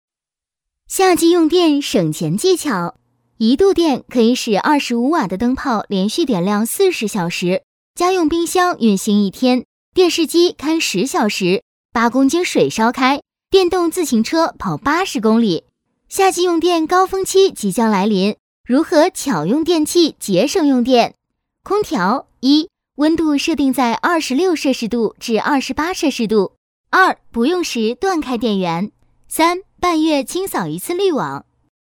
女218-飞碟说类型-夏季用电省钱技巧
女218-温柔甜美 轻松活泼